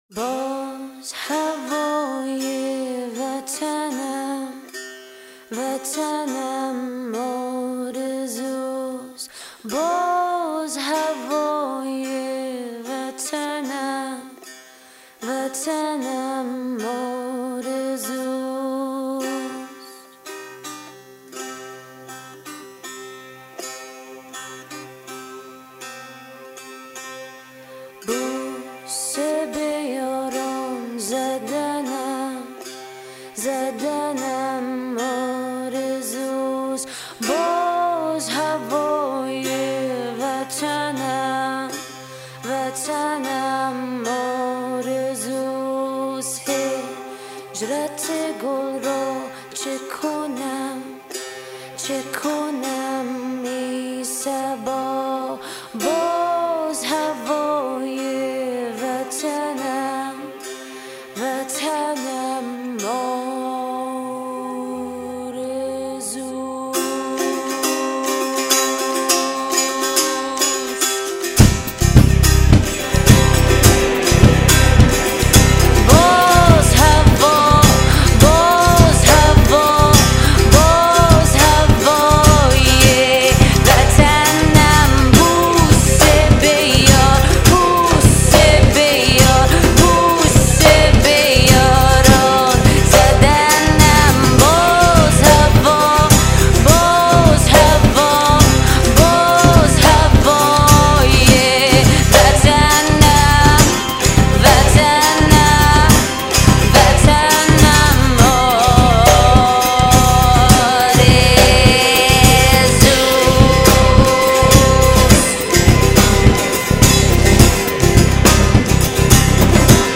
In one day we recorded three tunes live.